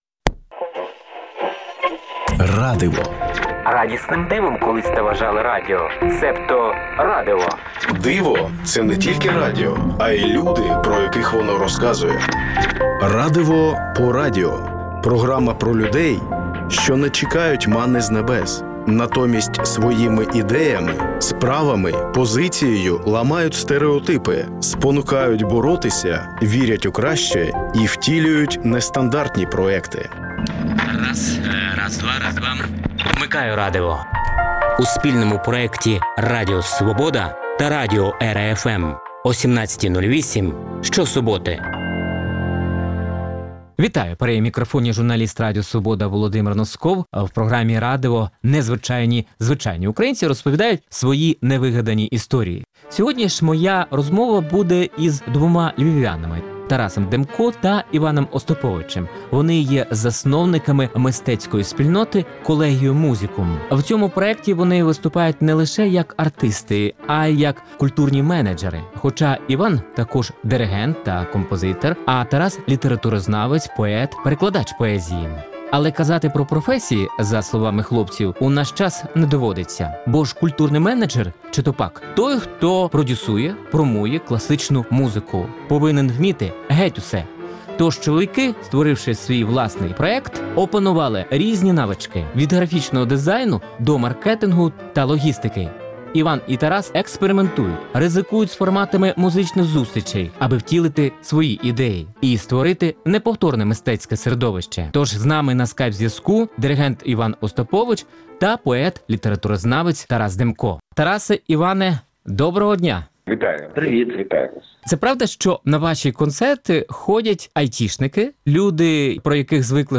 «Радиво» по радіо – це спільний проект Радіо Свобода та радіо «ЕРА-Fm» що присвячується людям, які не чекають манни з небес, натомість своїми ідеями, справами, позицією ламають стериотипи, спонукають боротися, вірять у краще і втілюють нестандартні проекти. Вмикайте «Радиво» що-суботи О 17.08. В передачі звучатимуть інтерв’ю портретні та радіо замальовки про волонтерів, військових, вчителів, медиків, громадських активістів, переселенців, людей з особливими потребами тощо.